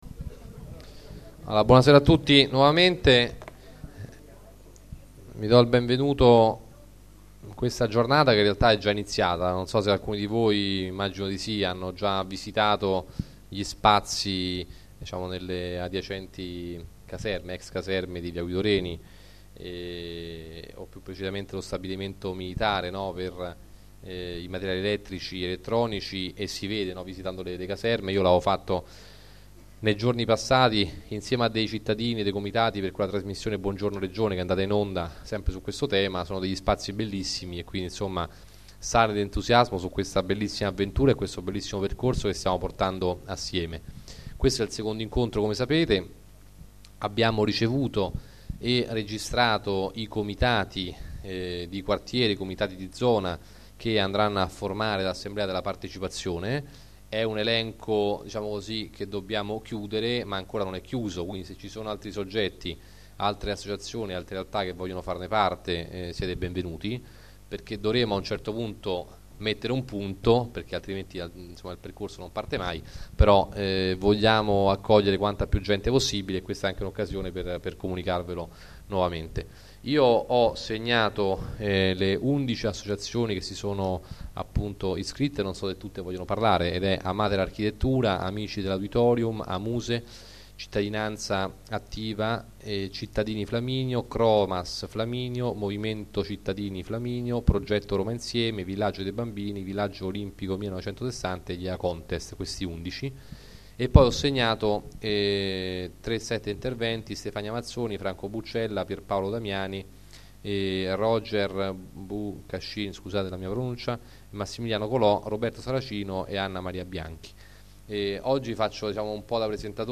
Registrazione integrale dell'incontro partecipativo svoltosi il 25 marzo 2014 nella sala del Cinema Tiziano
01-gerace On. Giuseppe Gerace, Presidente del Municipio Roma II